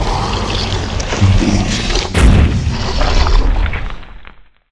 Index of /client_files/Data/sound/monster/dx1/